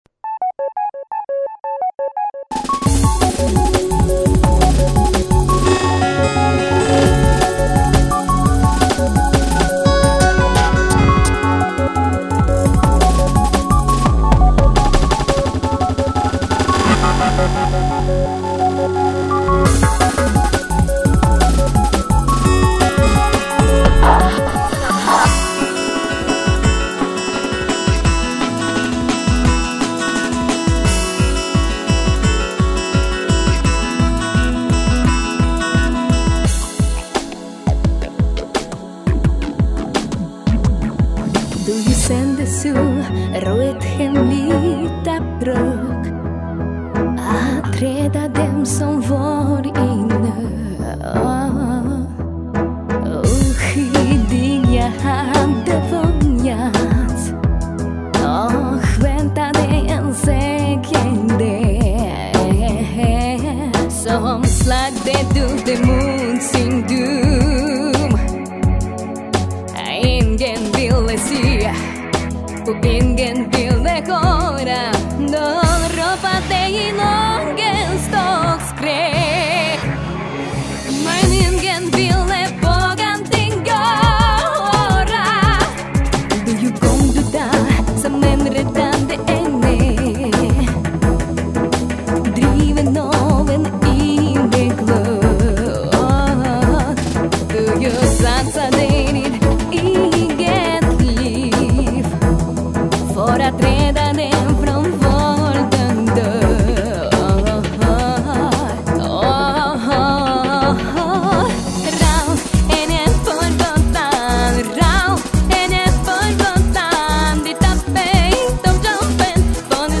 музыкальная тема